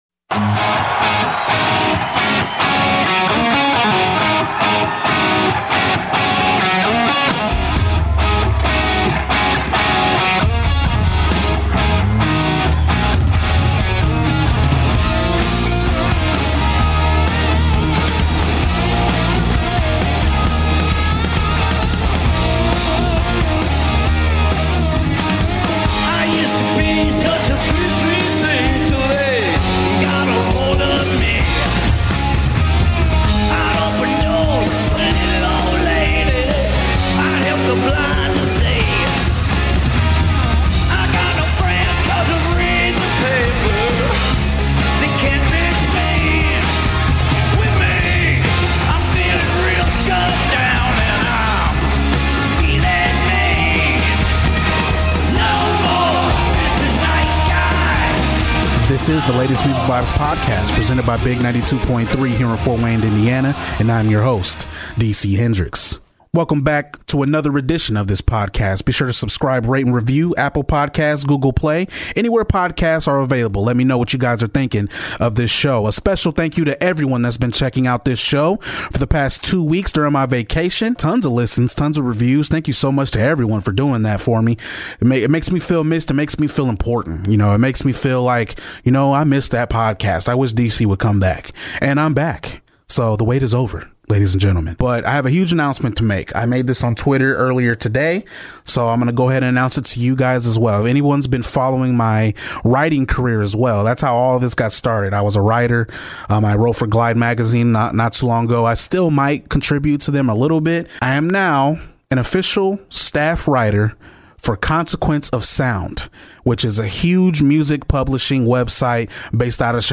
Throwback Interview